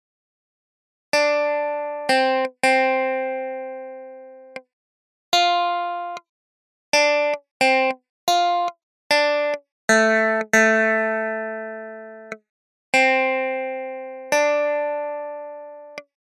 Key written in: F Major
Each recording below is single part only.
Other part 1:
a reed organ